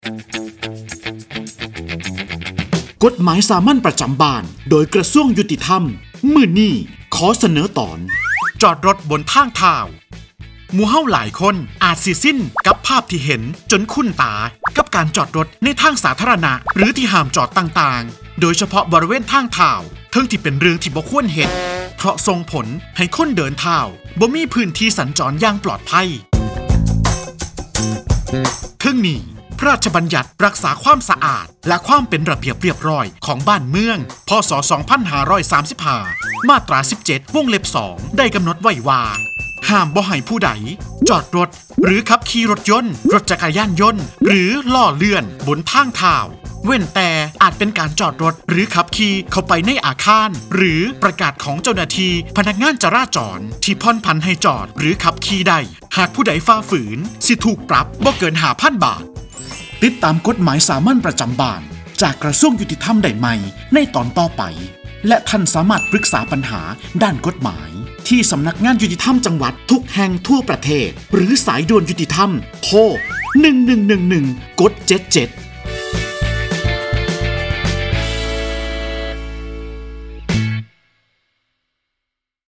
กฎหมายสามัญประจำบ้าน ฉบับภาษาท้องถิ่น ภาคอีสาน ตอนจอดรถบนทางเท้า
ลักษณะของสื่อ :   คลิปเสียง, บรรยาย